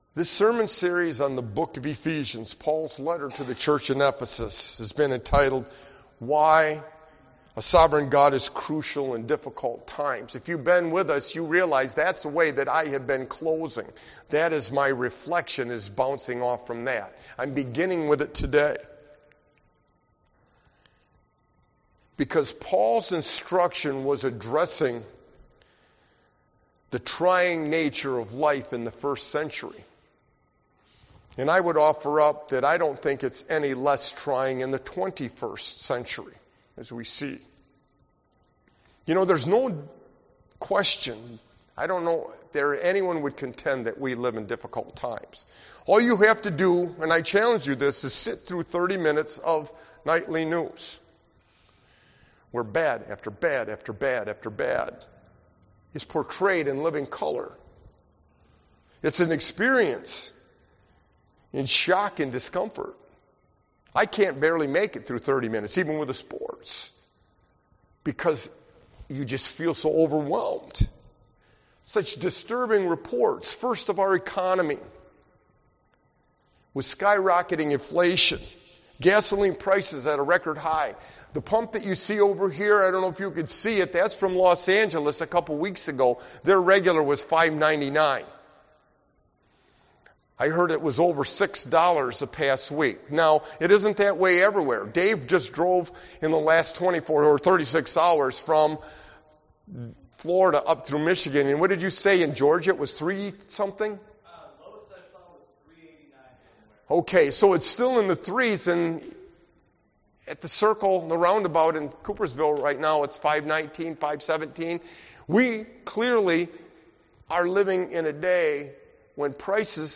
Sermon-Why-a-Sovereign-God-is-crucial-XVII-61222.mp3